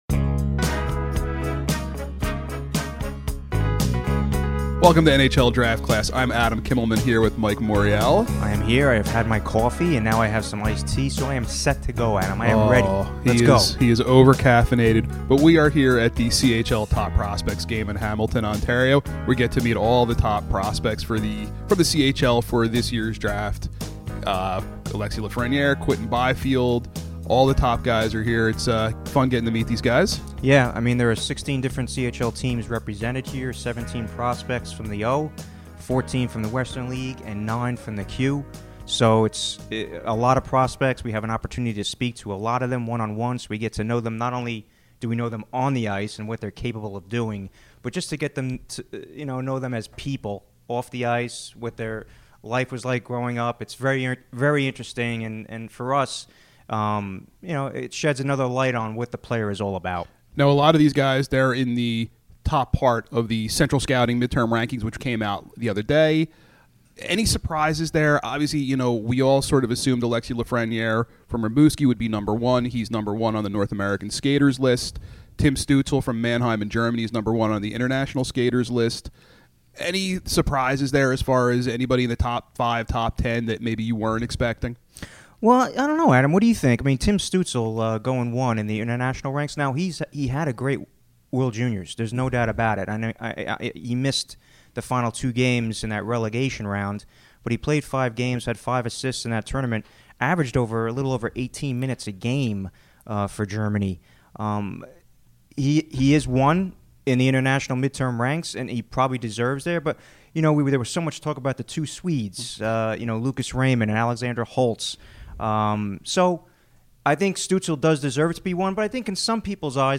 the CHL/NHL Top Prospects Game in Hamilton, Ontario. They break down the latest prospect rankings (1:00) and chat with Quinton Byfield (3:56) and Alexis Lafrenière (16:00). Plus, they discuss their Mock Draft 1.0 (27:10).